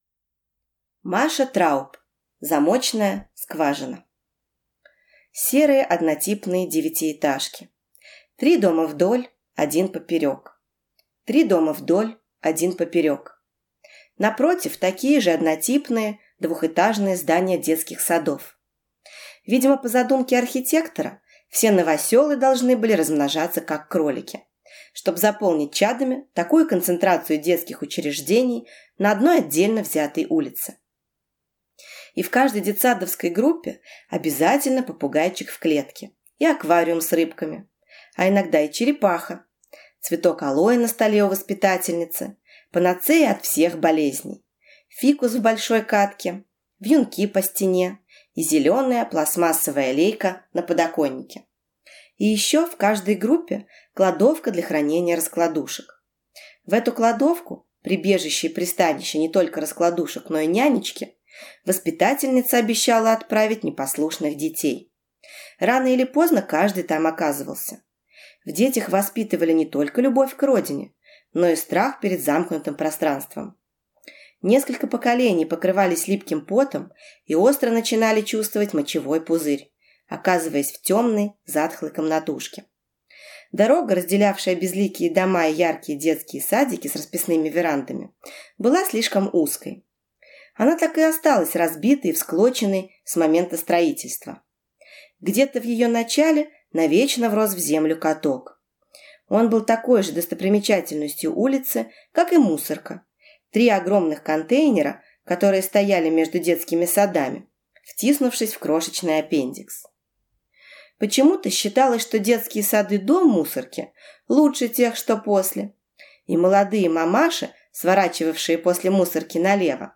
Аудиокнига Замочная скважина | Библиотека аудиокниг